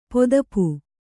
♪ podapu